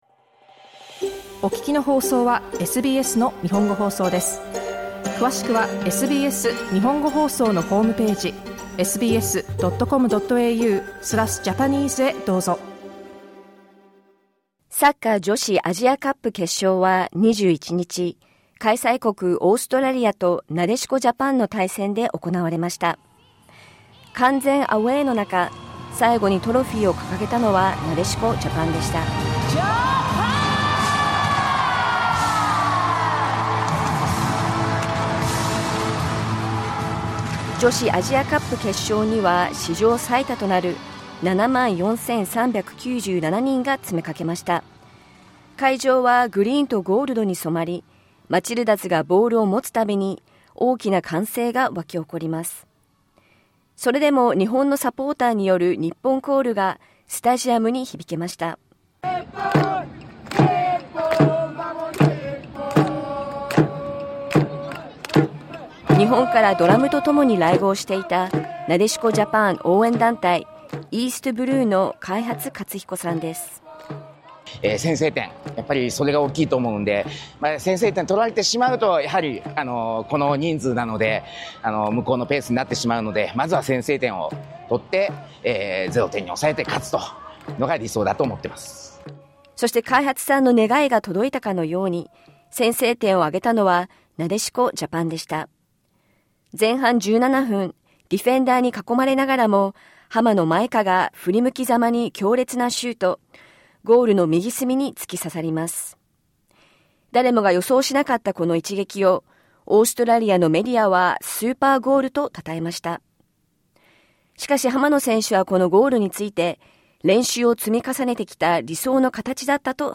Nadeshiko Japan have been crowned champions of Asia after a 1–0 win over the Matildas. SBS Japanese spoke to standout players from the final, as well as supporters who travelled all the way from Japan to cheer them on.